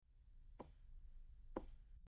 Quiet Footsteps
quiet_footsteps.mp3